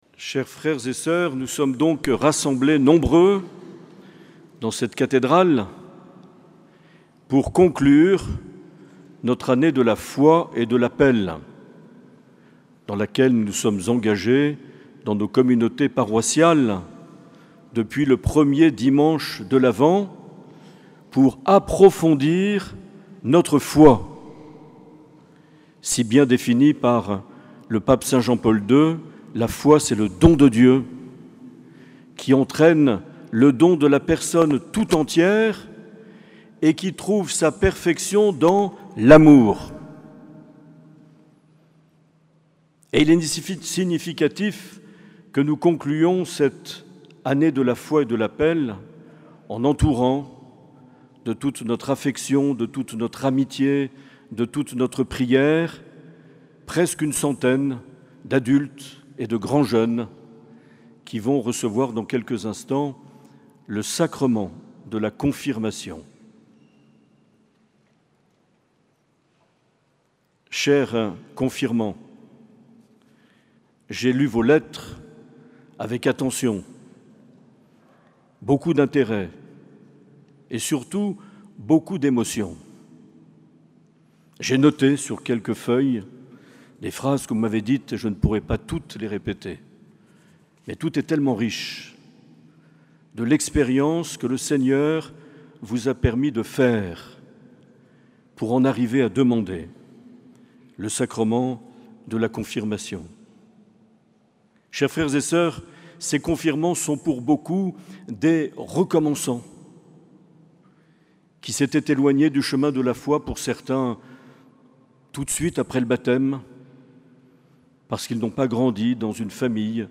Homélie de Mgr Marc Aillet lors de la messe de la Vigile de Pentecôte.